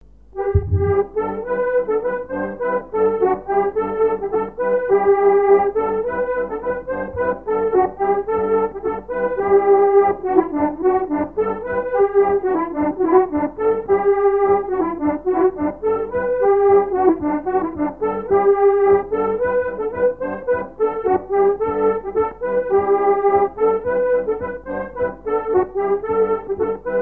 Notre-Dame-de-Riez
Chants brefs - A danser
danse : gigouillette
Pièce musicale inédite